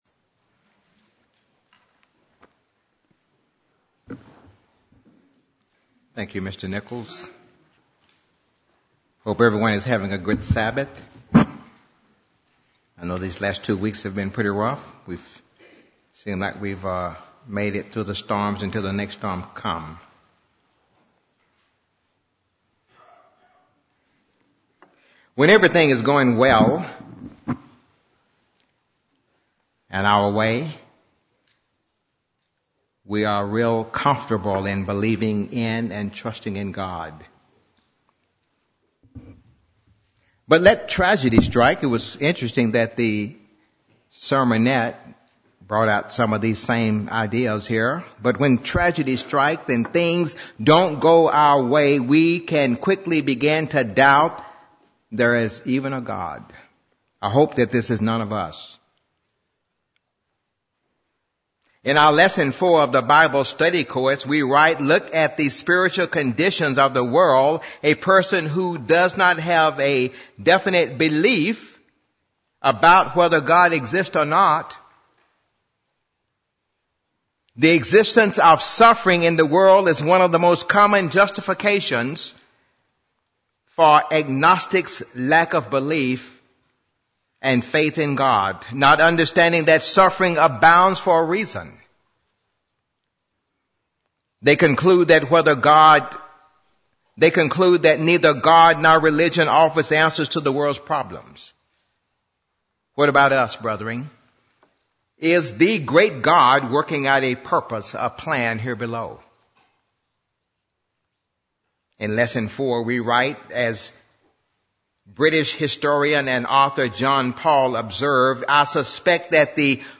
Given in Nashville, TN
UCG Sermon Studying the bible?